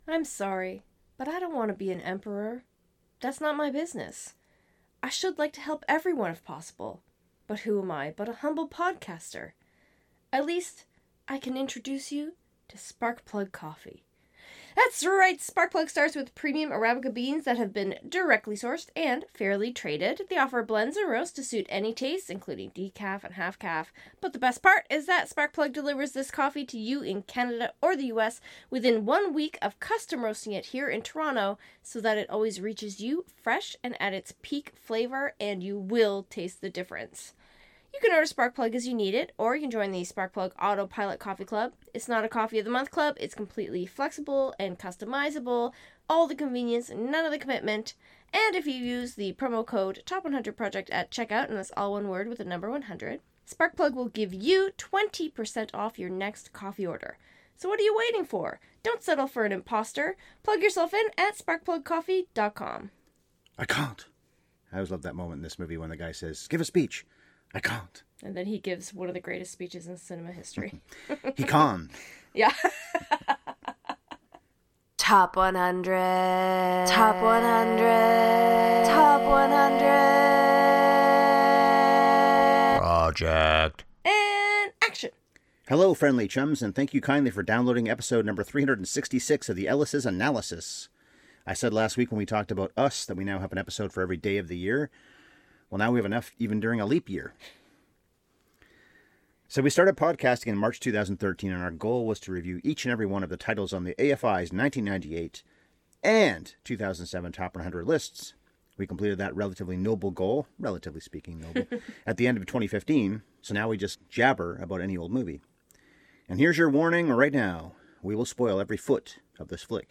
Rated R because this got political and angry.